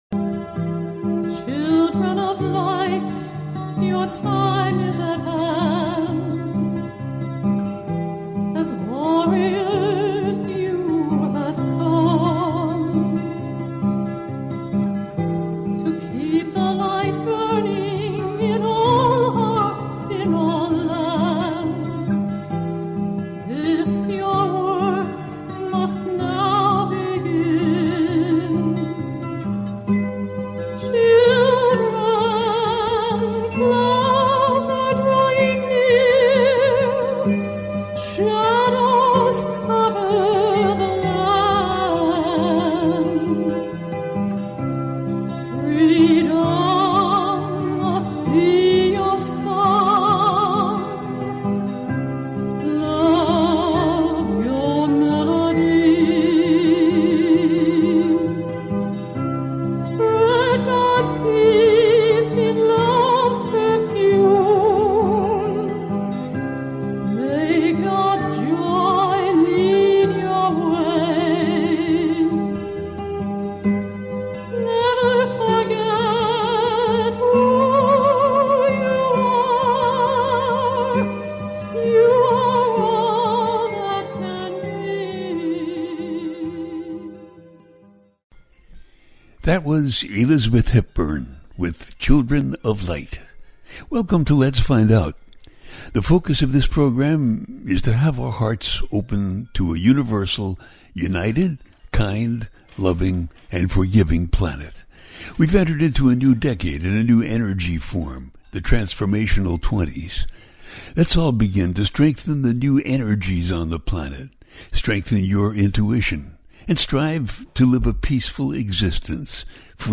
The New Moon In Scorpio 2024 - A teaching show